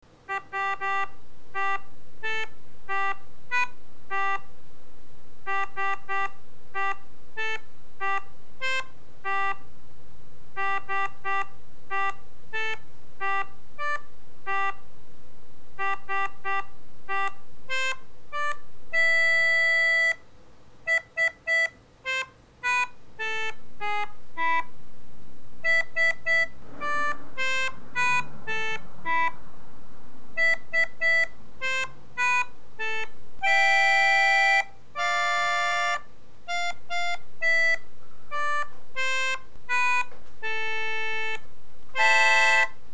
Сыграл телефоном, используя приложение к Андроиду, Anglitina. Точно по нотам.